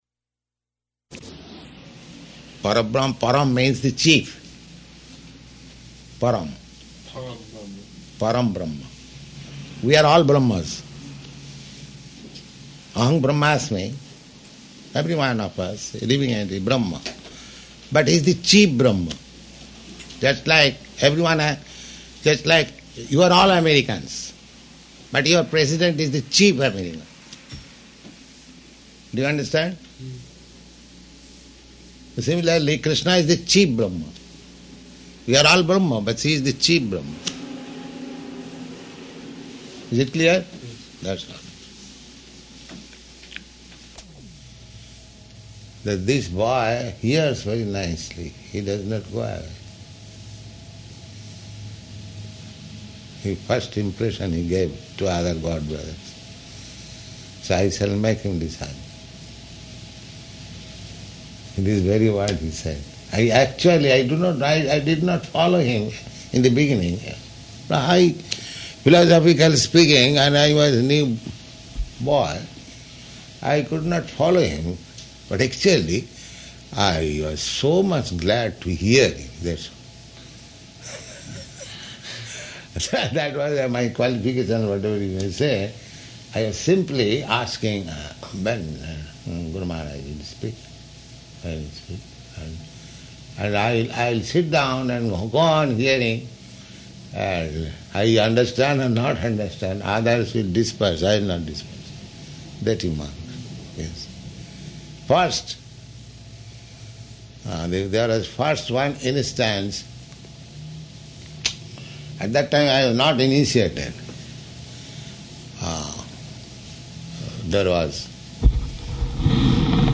Room Conversation
Room Conversation --:-- --:-- Type: Conversation Dated: May 10th 1969 Location: Colombus Audio file: 690510R1-COLUMBUS.mp3 Prabhupāda: Param Brahman.